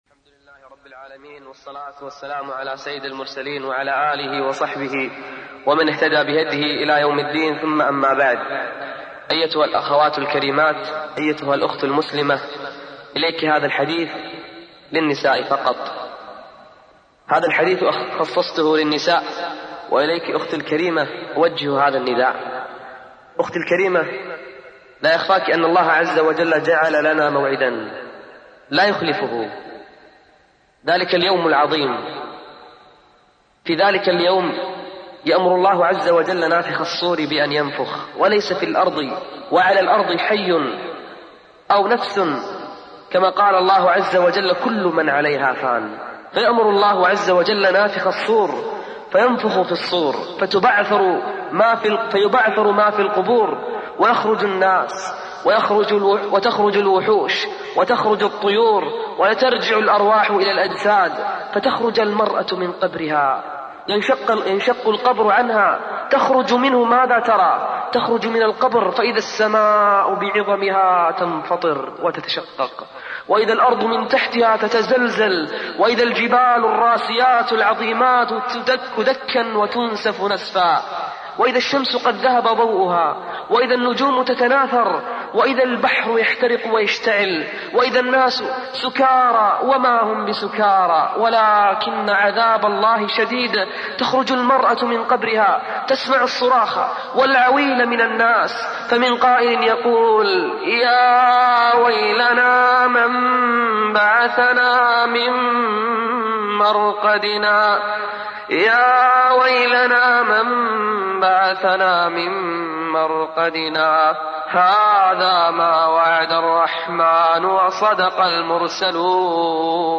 المحاضرات